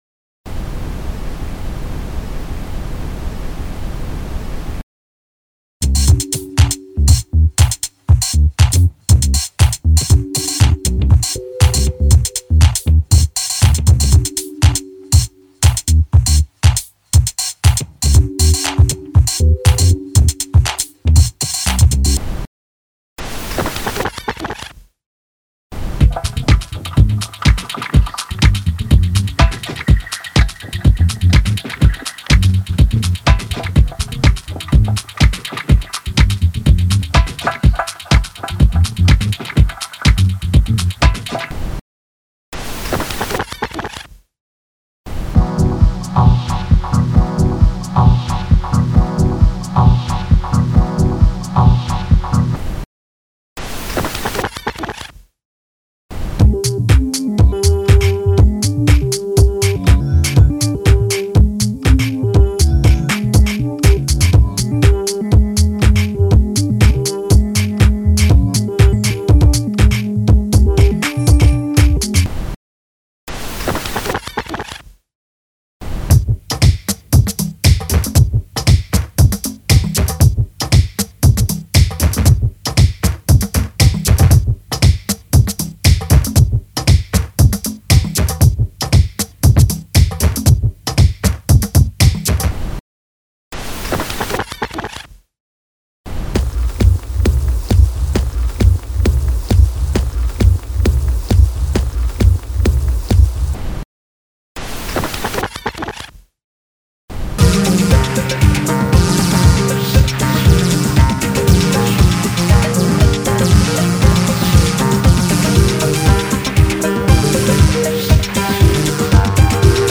... electronic musiq